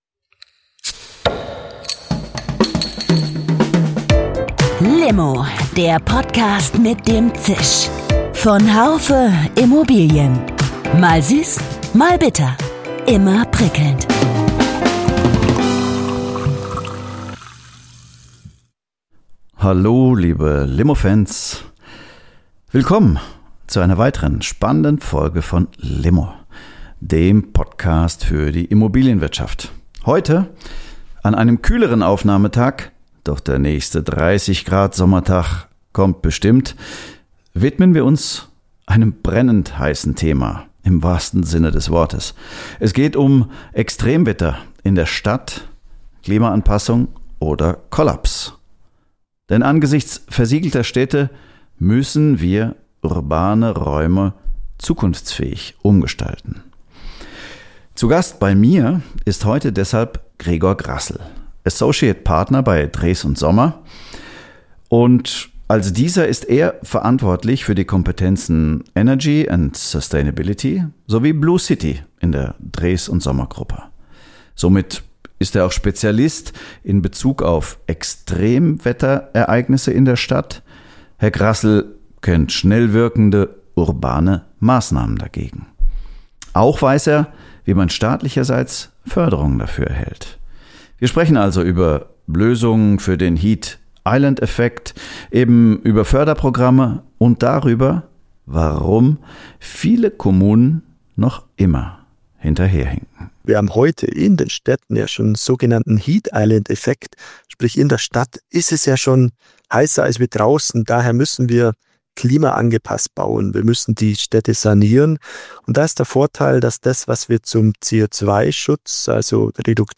Ein Gespräch über Lösungen für den Heat-Island-Effekt, eben über Förderprogramme und darüber, warum viele Kommunen immer noch hinterherhinken.